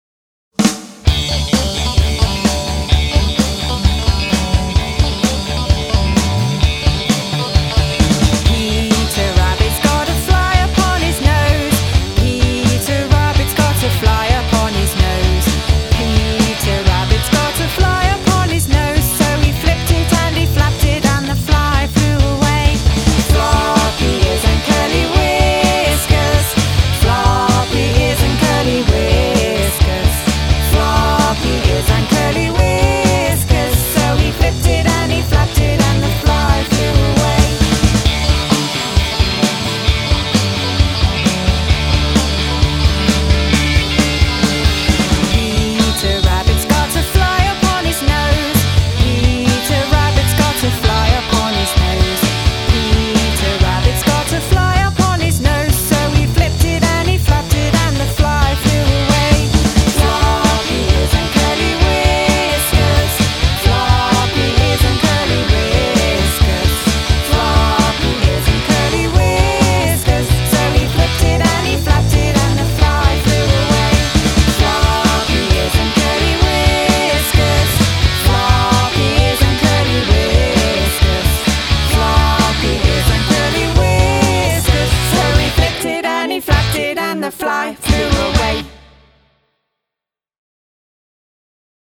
Fun & Punk